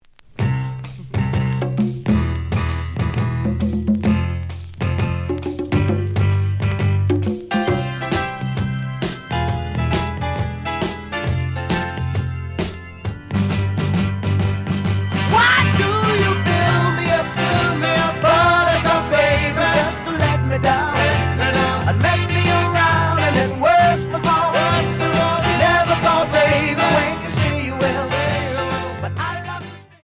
The audio sample is recorded from the actual item.
●Genre: Rock / Pop
Edge warp. But doesn't affect playing. Plays good.)